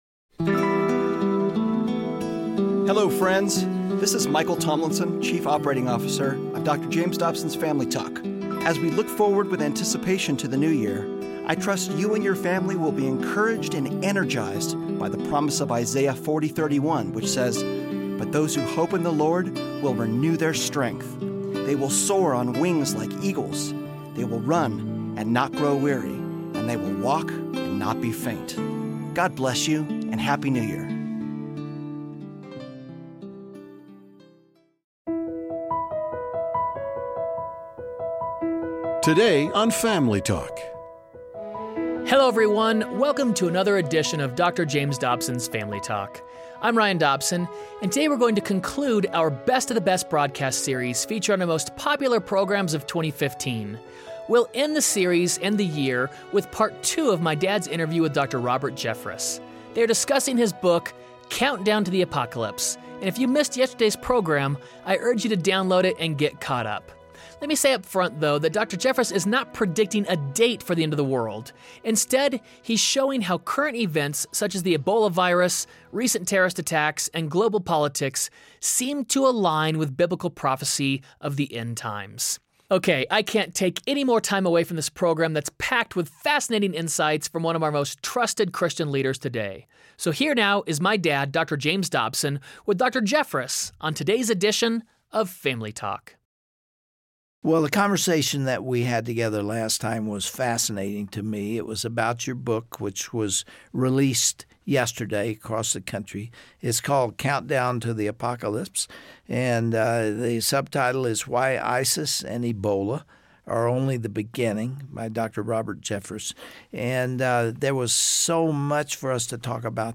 But what if this is all part of a divine plan? On the next edition of Family Talk, Dr. James Dobson interviews Dr. Robert Jeffress on the countdown to the Apocalypse.